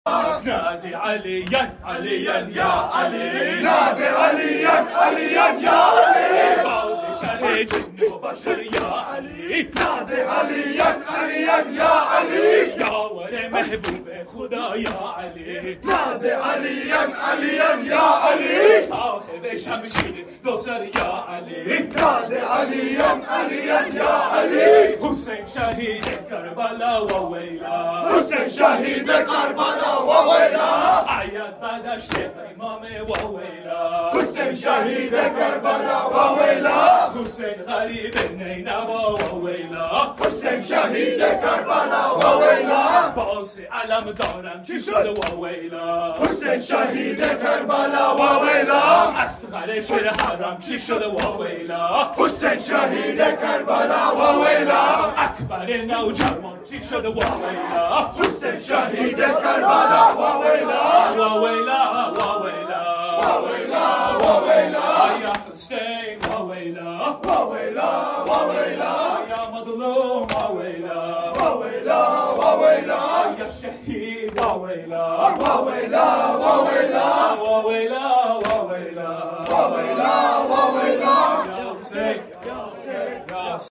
Ending Matams